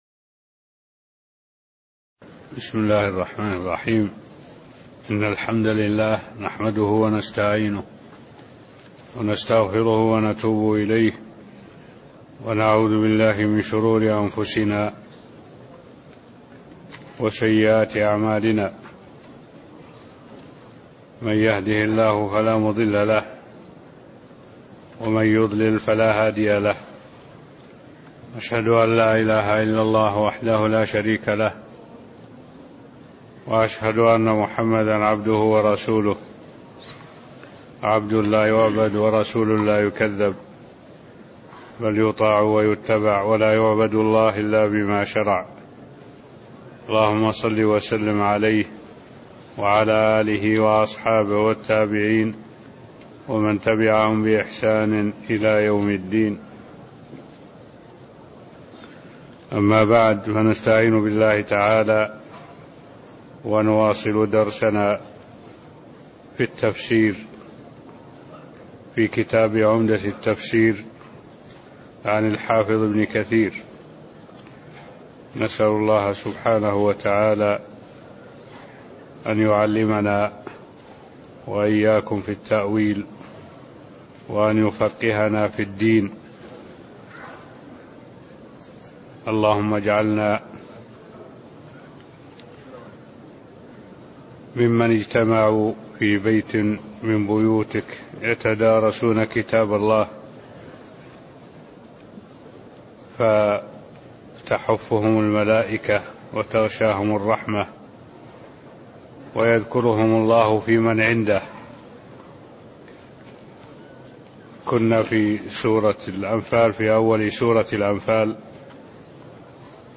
المكان: المسجد النبوي الشيخ: معالي الشيخ الدكتور صالح بن عبد الله العبود معالي الشيخ الدكتور صالح بن عبد الله العبود آية رقم 1-8 (0387)